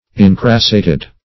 Incrassate \In*cras"sate\, Incrassated \In*cras"sa*ted\, a. [L.